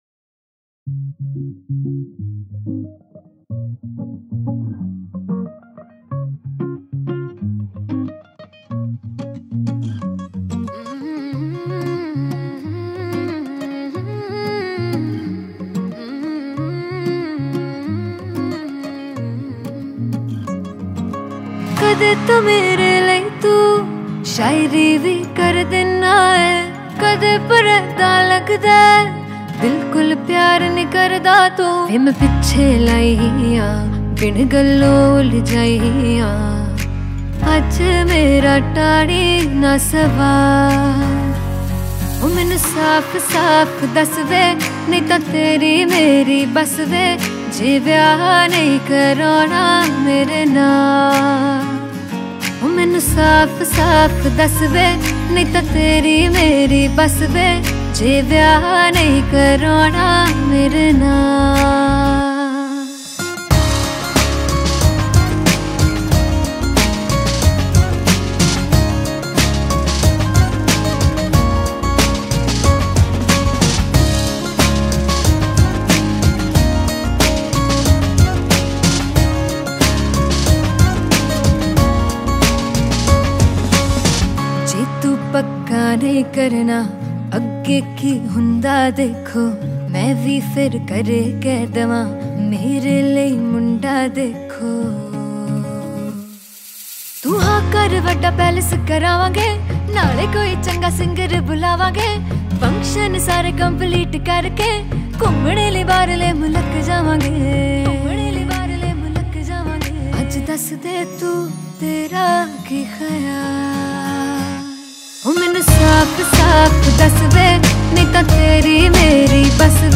Punjabi Bhangra MP3 Songs
Indian Pop